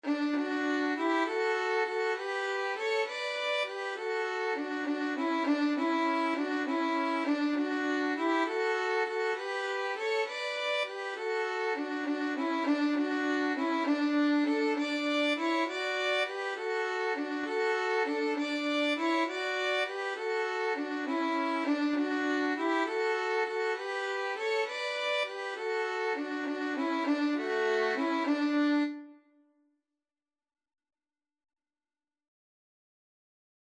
Christian
Violin 1Violin 2
6/8 (View more 6/8 Music)
Violin Duet  (View more Easy Violin Duet Music)
Classical (View more Classical Violin Duet Music)